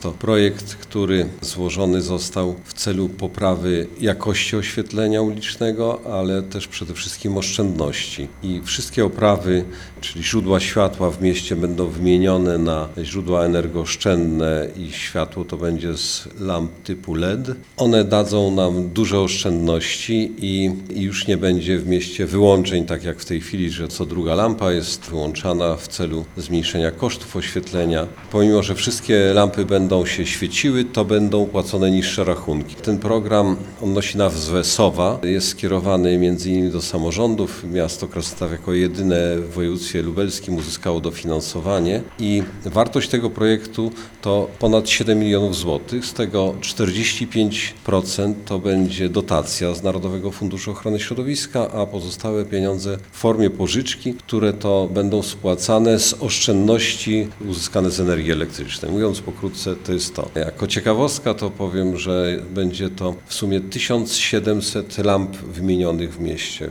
- Spodziewamy się dużych oszczędności. Będzie to operacja na dużą skalę, ale dzięki modernizacji będziemy mieli najnowocześniejszy system oświetlenia ulicznego na Lubelszczyźnie - mówi burmistrz Krasnegostawu Andrzej Jakubiec